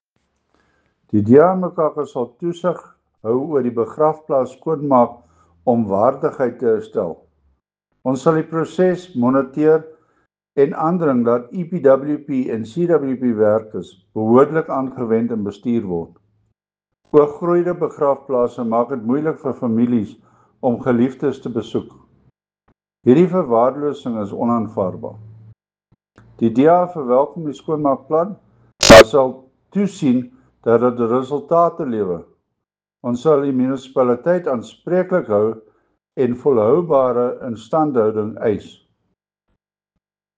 Afrikaans soundbites by Cllr Chris Dalton and